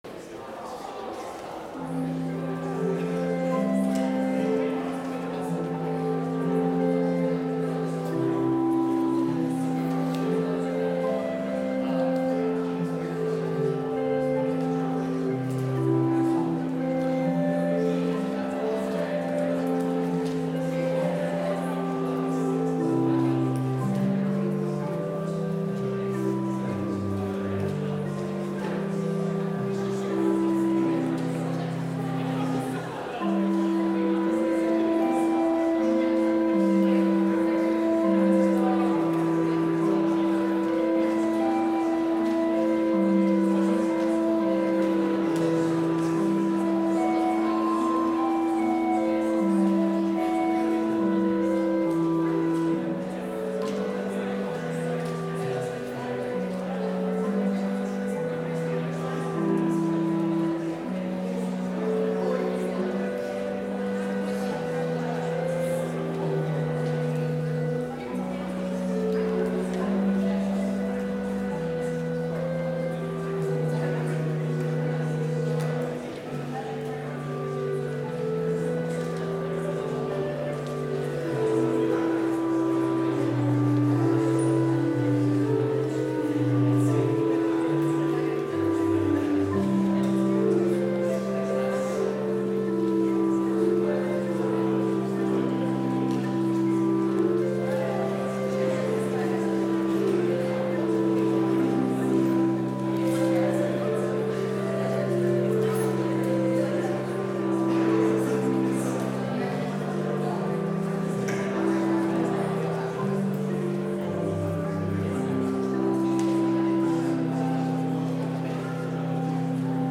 Complete service audio for Chapel - October 26, 2021